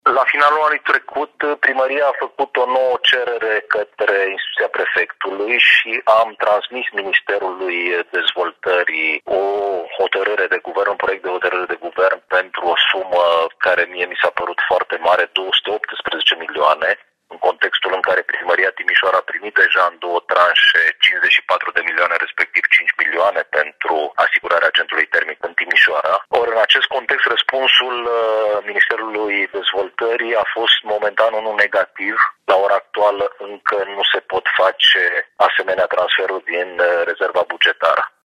Explicațiile vin de la subprefectul Ovidiu Drăgănescu.